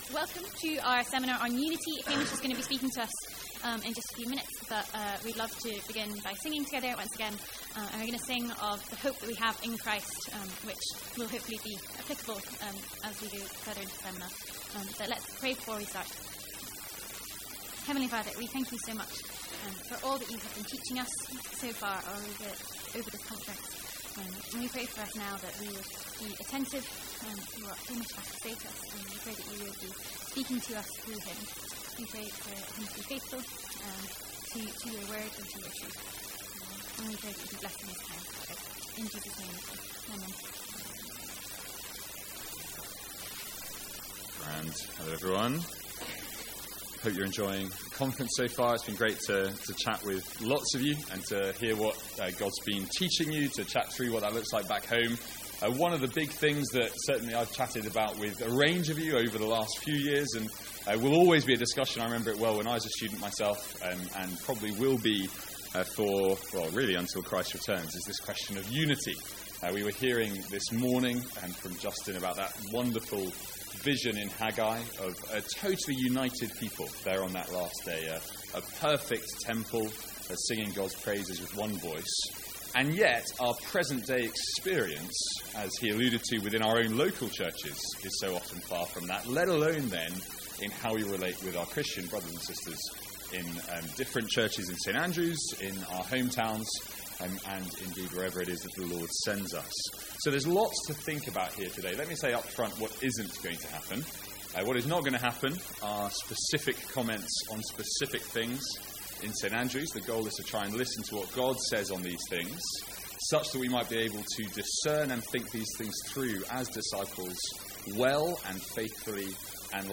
A seminar on unity from MYC 2017.